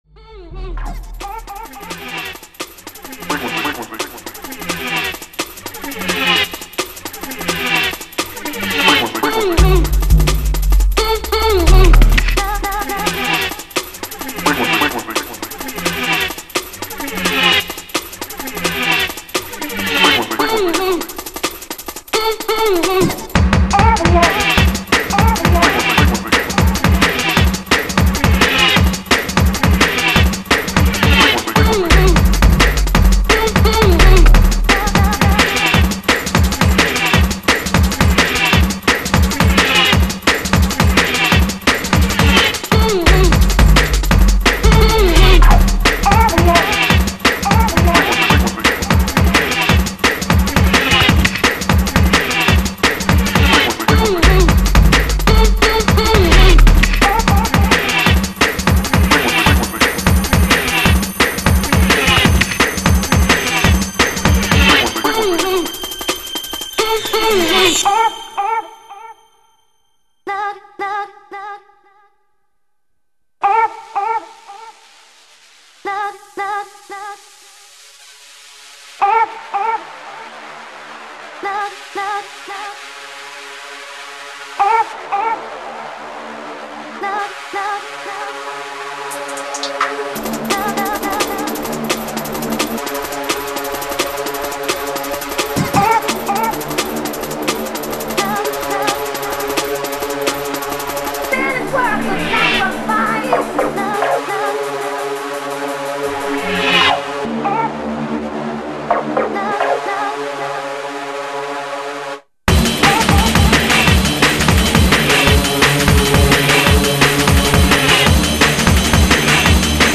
futuristic dark sounds